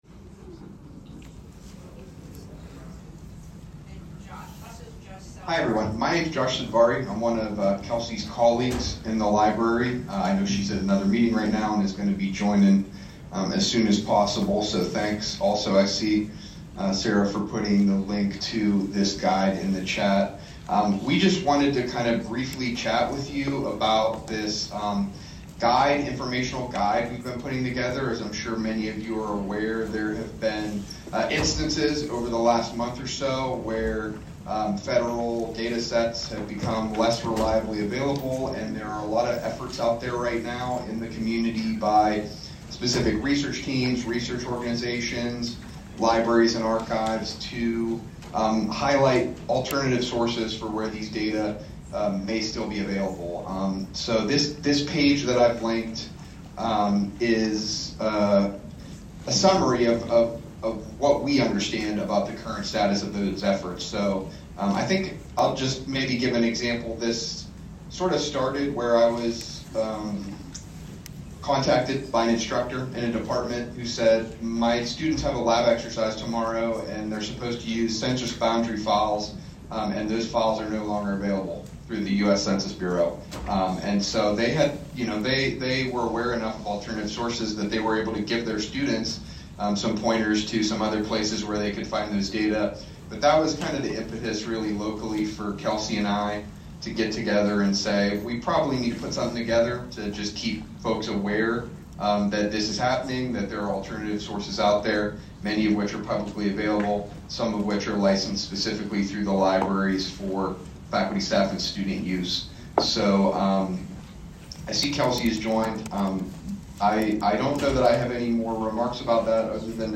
The University Senate will convene at 3:30 PM, Thursday February 27, 2025 in person in the Saxbe Auditorium, Drinko Hall. This meeting will be a hybrid meeting.
President Ted Carter will be the presiding officer.